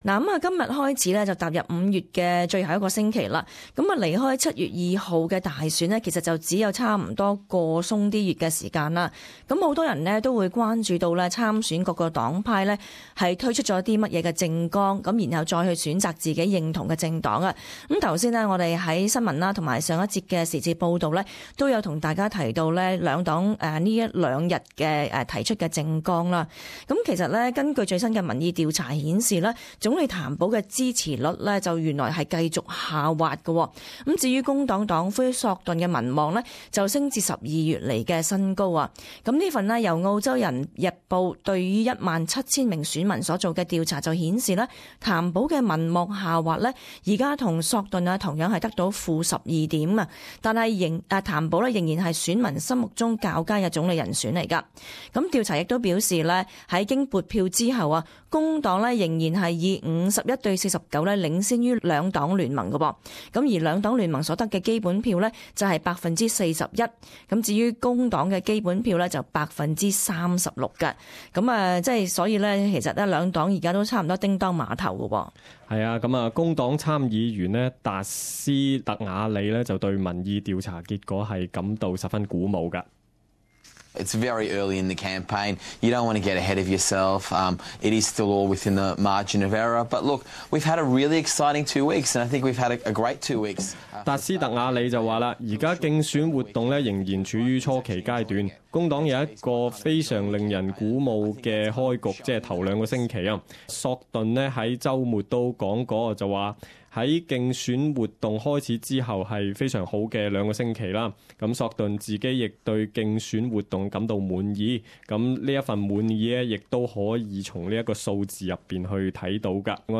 時事報導 － 年輕人投票登記率偏低